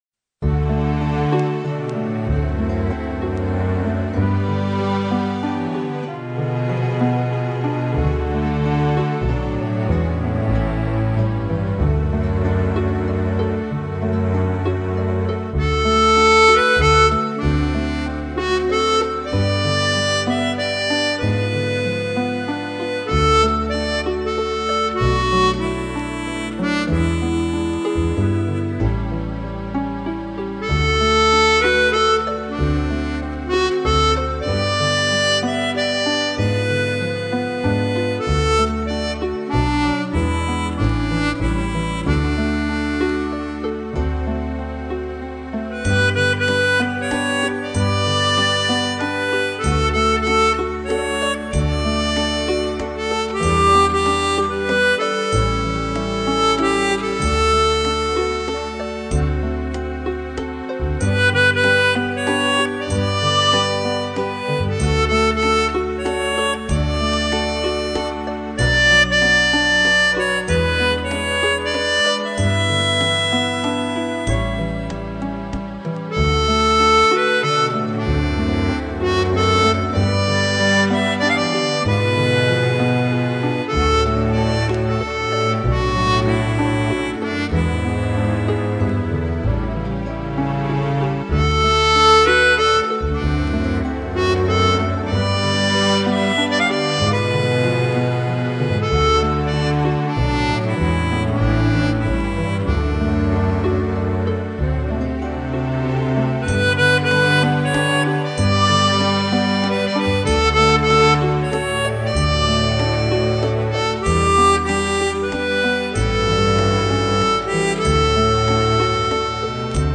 Elle est en ré majeur pour les diatoniciens.
Les "comas" entre sont un peu trop nombreux.